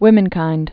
(wĭmən-kīnd)